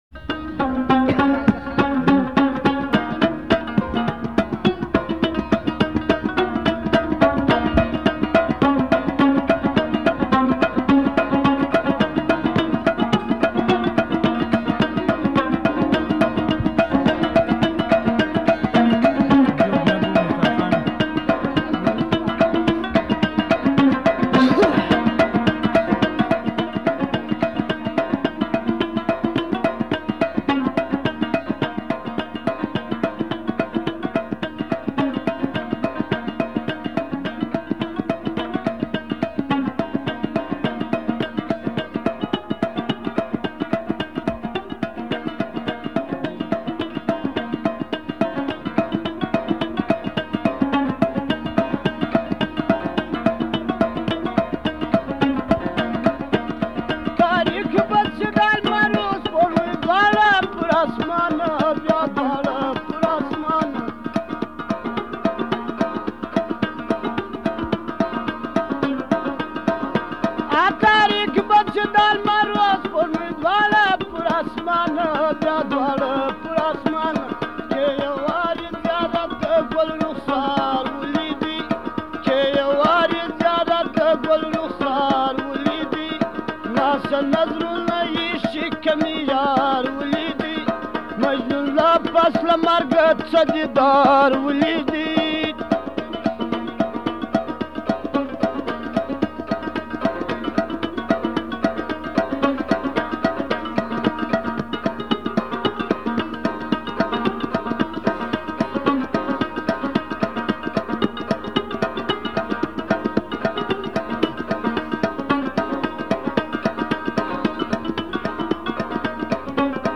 Pashtu Ghazal (música Pastún)